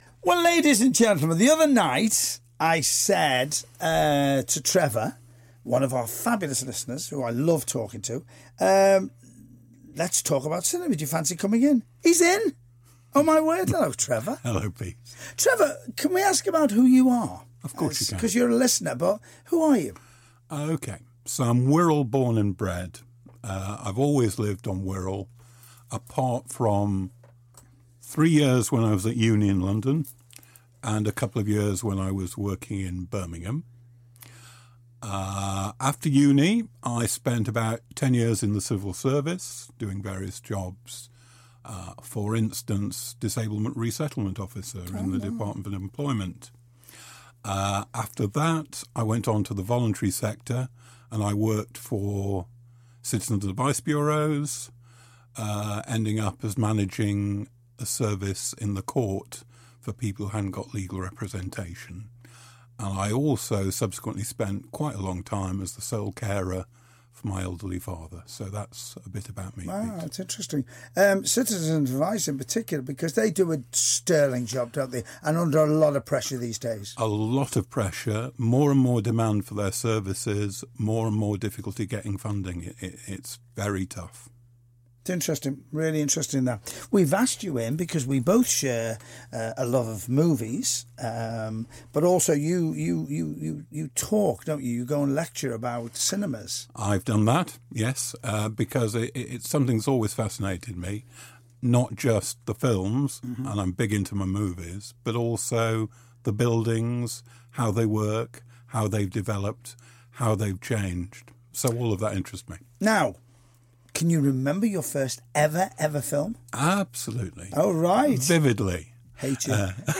Popular caller
in the studio to talk about the subject of Cinema !